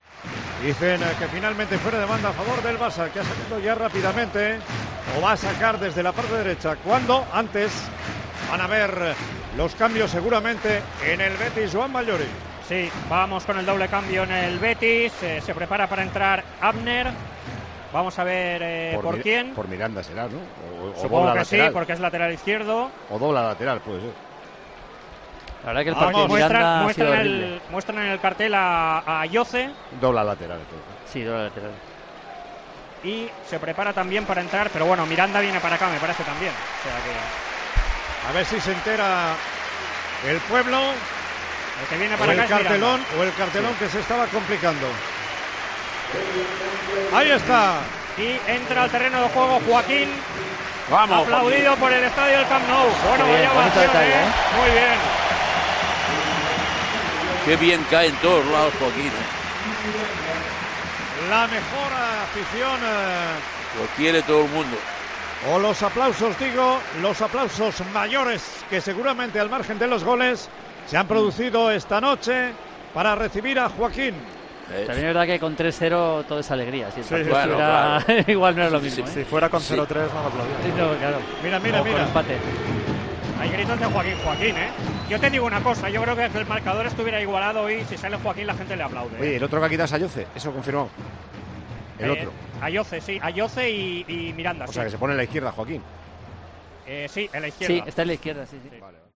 Así sonó la ovación del Camp Nou a Joaquín, en Tiempo de Juego
Con Paco González, Manolo Lama y Juanma Castaño